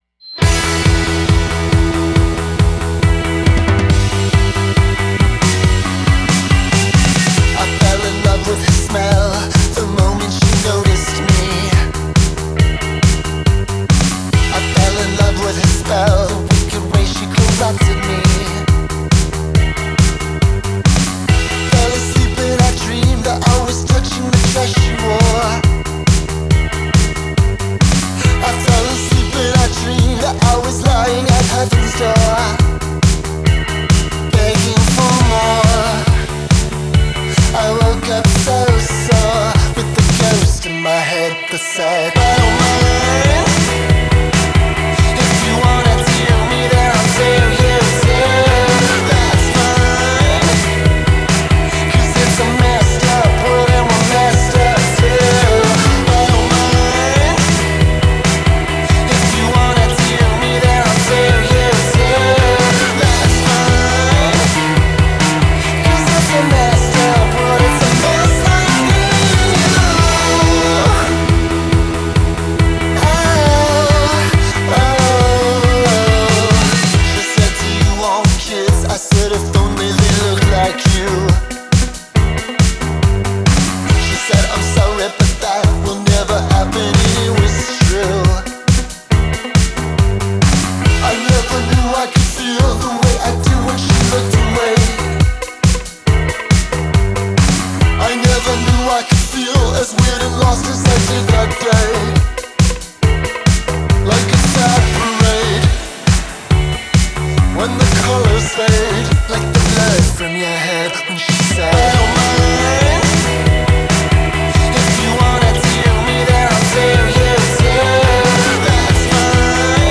Genre: Indie, Dance Punk